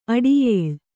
♪ aḍiyēł